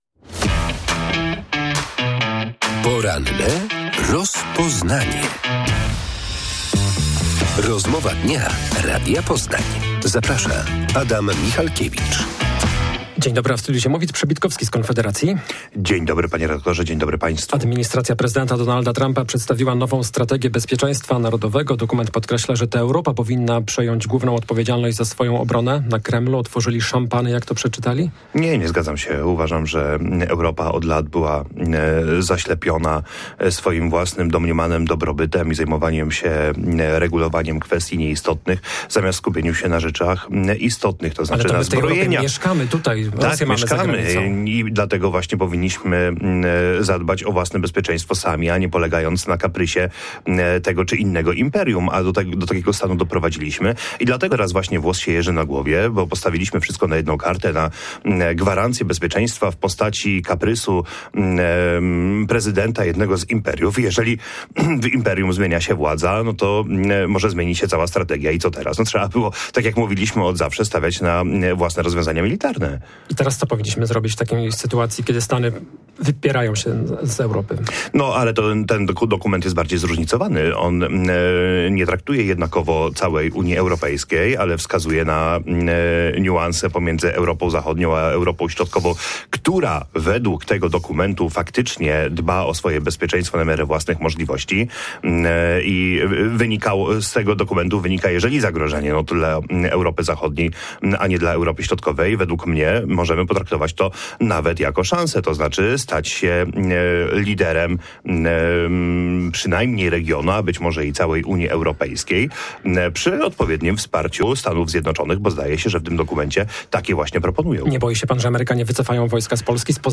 w porannej rozmowie Radia Poznań przekonywał, że konieczne są gruntowne reformy.